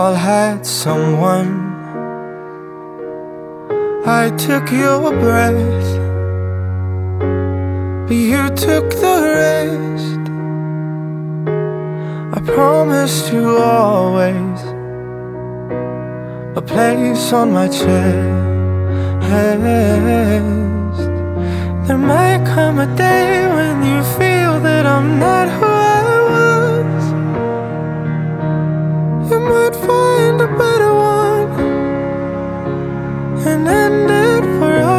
# Vocal